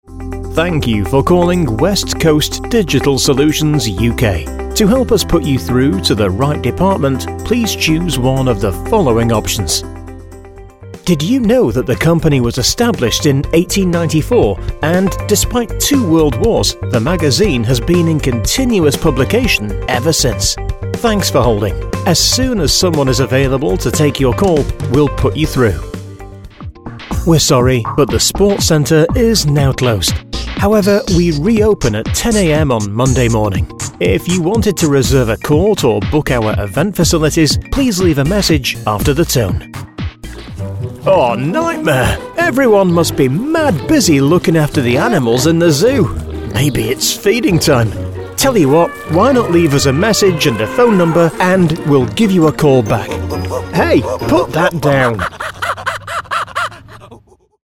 Englisch (Britisch)
Natürlich
Konversation
Freundlich